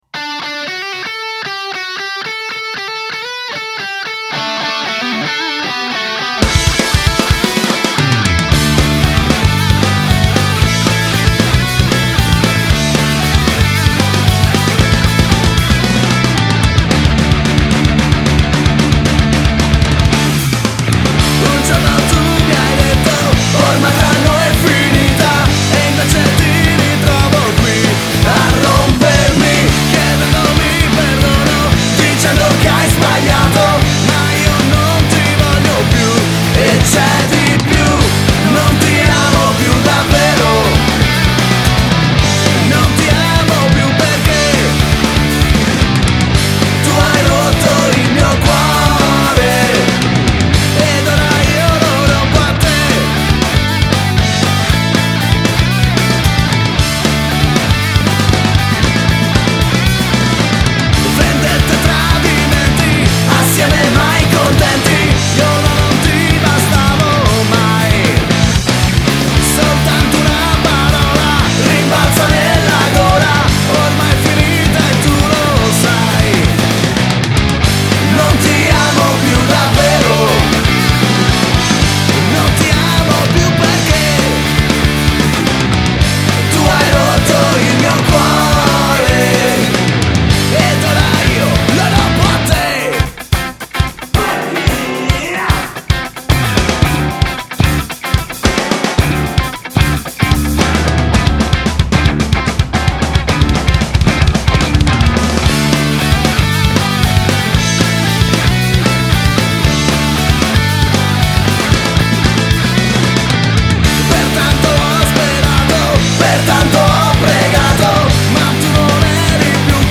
recensioni e punk hardcore italiano dal 2003.